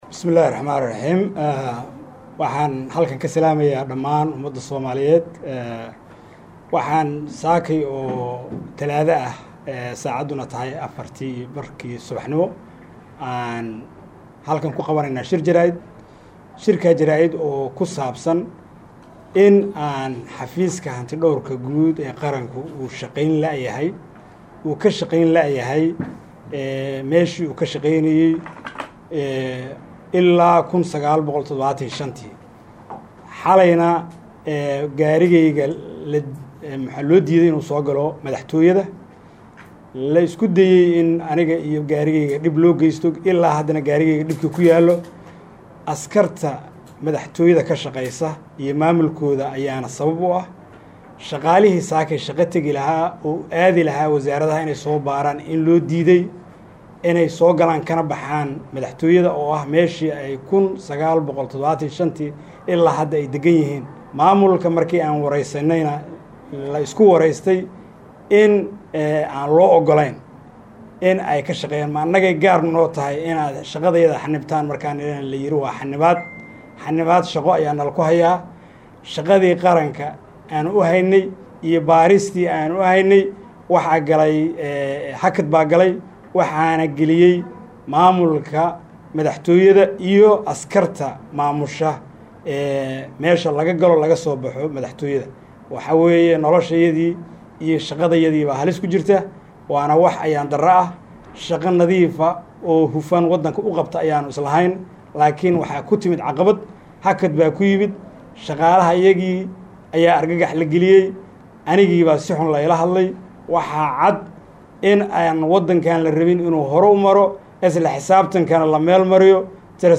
Muqdisho(INO)-Hanti Dhowraha Guud ee Qaranka Dr. Nuur Faarax Jimcaale oo Maanta Shir jiraa’id ku qabtay Magaalada Muqdisho ayaa sheegay in loo diiday Shaqadii uu u hayay Shacabka Soomaaliyeed isla markaan ay istaagtay Shaqadii Xafiiskiisa.